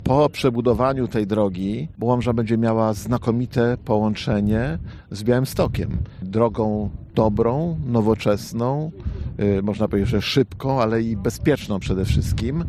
Starosta Łomżyński Lech Marek Szabłowski mówił, że remontowany odcinek to najlepsze i najszybsze połączenie Łomży oraz powiatu z miastem wojewódzkim: